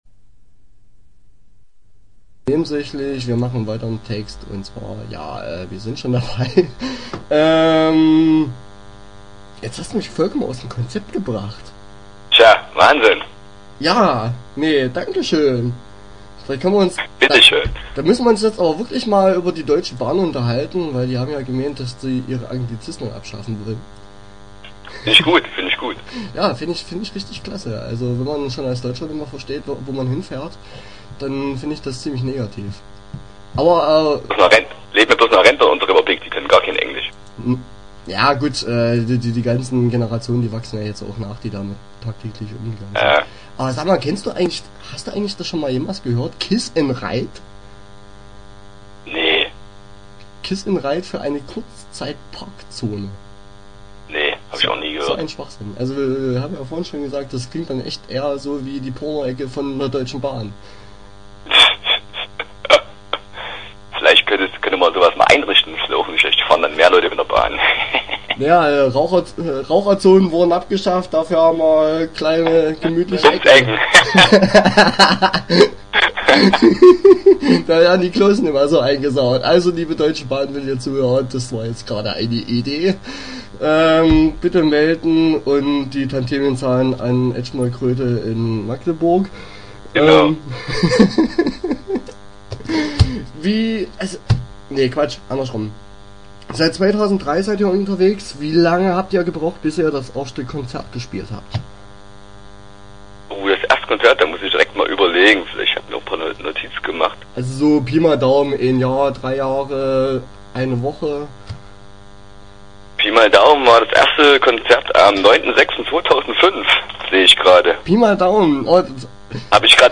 Interview Teil 1 (6:41)